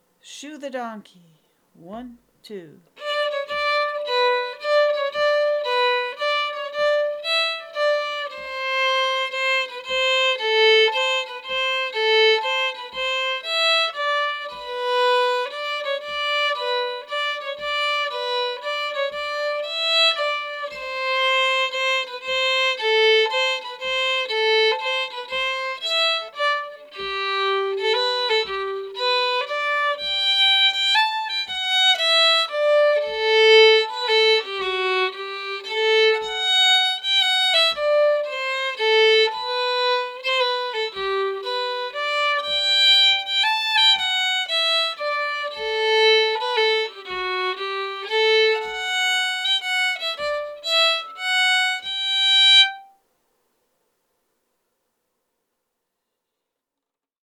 Key: G
Form: Mazurka
M: 3/4